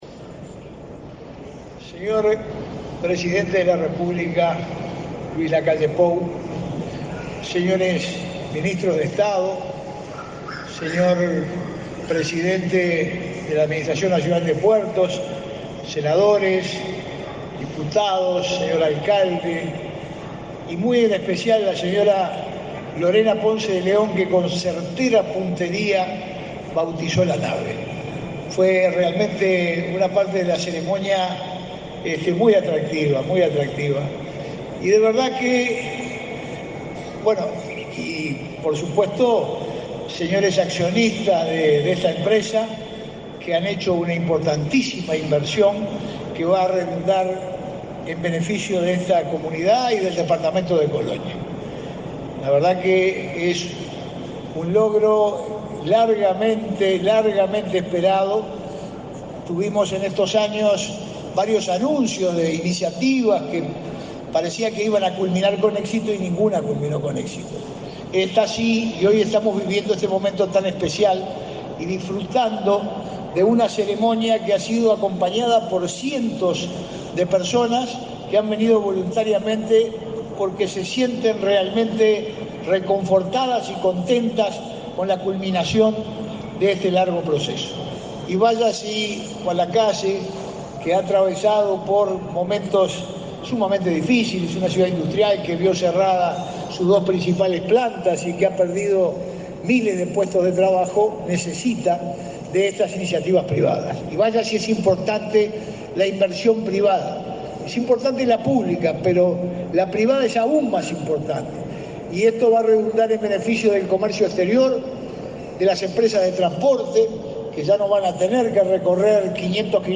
Conferencia de prensa por presentación de compañía fluvial y bautismo de buque, en Juan Lacaze